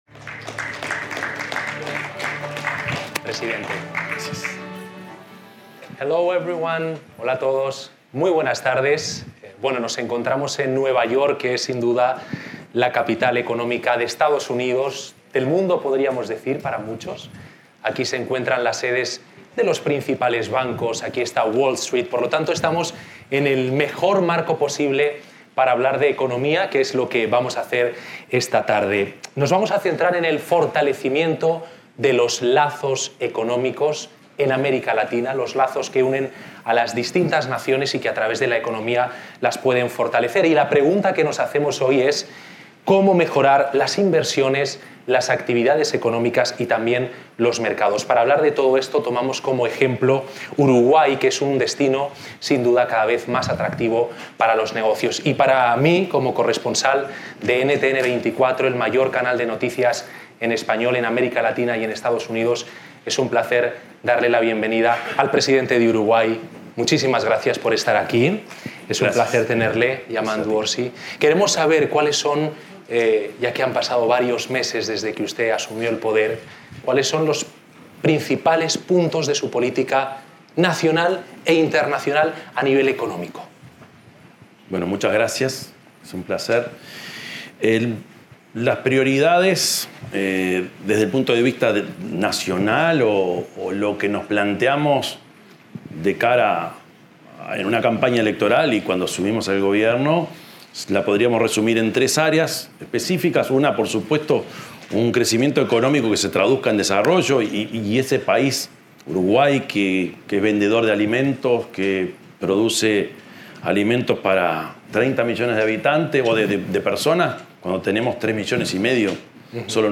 Declaraciones del presidente Yamandú Orsi
Declaraciones del presidente Yamandú Orsi 22/09/2025 Compartir Facebook X Copiar enlace WhatsApp LinkedIn El presidente de la República, Yamandú Orsi, participó en la Cumbre Anual Concordia 2025, donde fue entrevistado previo a su intervención en la Asamblea General de las Naciones Unidas de este martes 23.